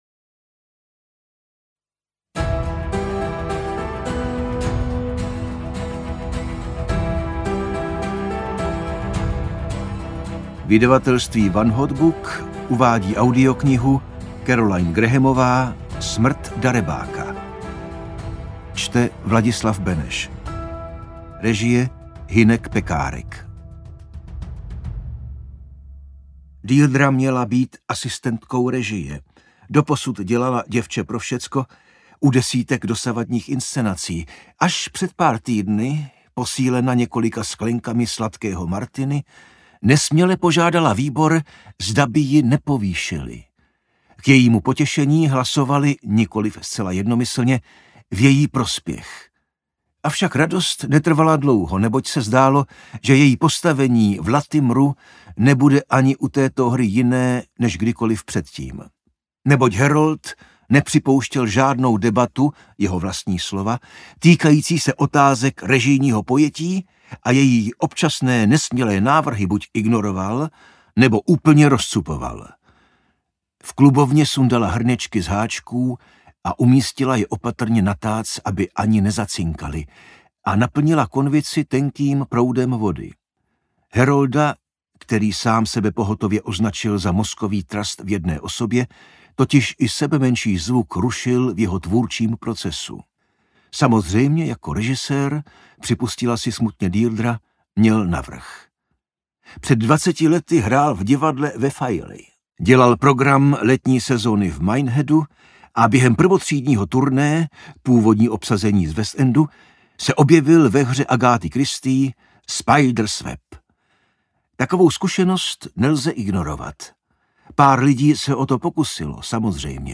Smrt darebáka audiokniha
Ukázka z knihy
• InterpretVladislav Beneš